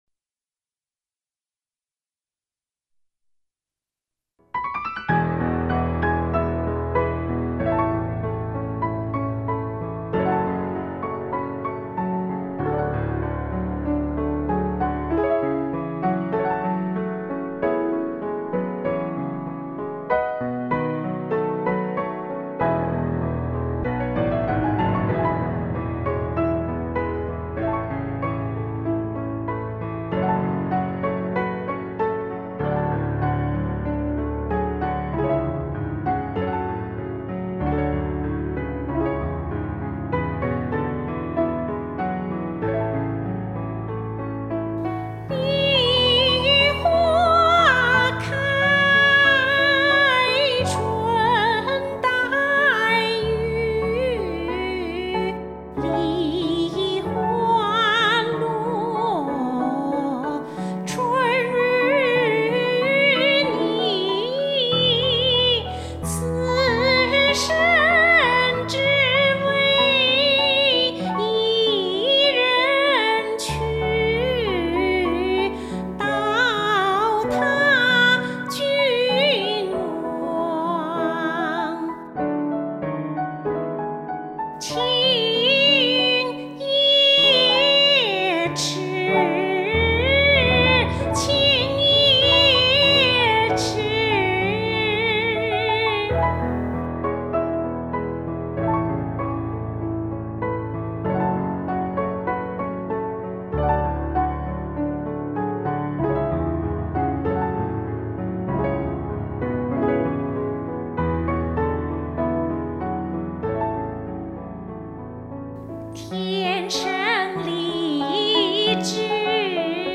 最后这个合奏集锦包括笛子分别与手风琴、钢琴、小号的合奏。
手风琴声音清脆流利，欢快跳跃，如行云流水，令人愉悦舒畅。
钢琴伴奏激昂流畅，动人心魄
这是小号和笛子二重奏，小号引领开场，后面又加了几段笛子变奏及口哨。号声嘹亮豪迈，乐曲轻快诙谐，效果相当出彩。